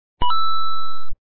SFX游戏中奖背景音效下载
SFX音效